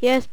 horseman_select2.wav